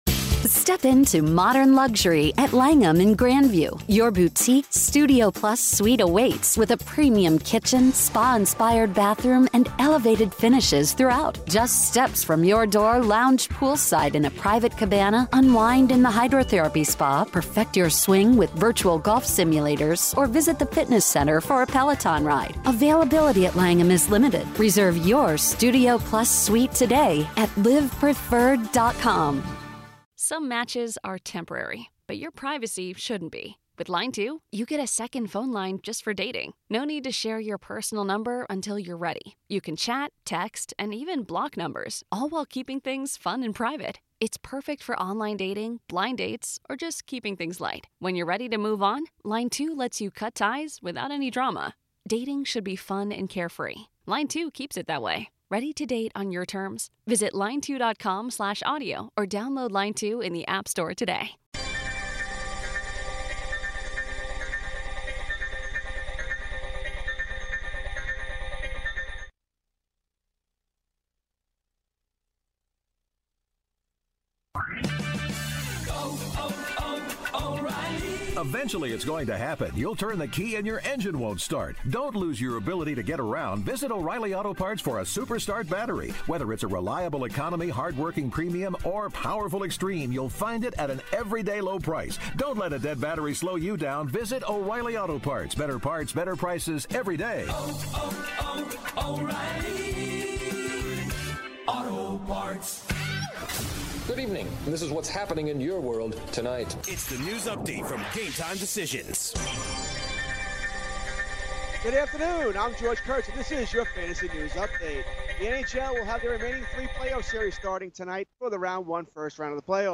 Re-Air of Ken Daneyko Interview